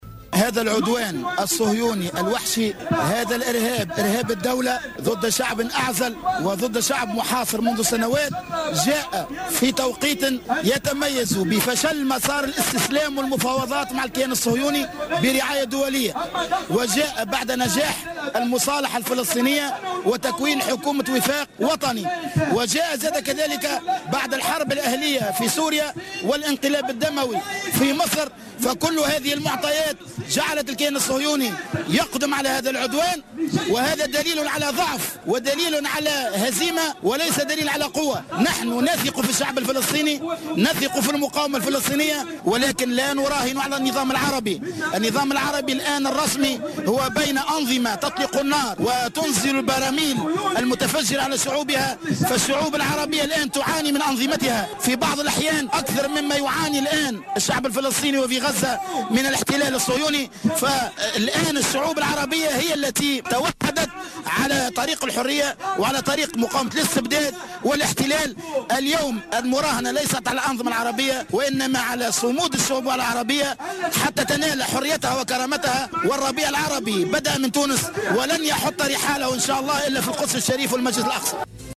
Le leader au sein du mouvement Ennahdha Abdelkarim Harouni a déclaré au micro de Jawhara FM que désormais il faut ne faut plus compter sur les dirigeants des pays arabes mais plutôt miser sur les peuples pour arracher leur liberté et leur dignité.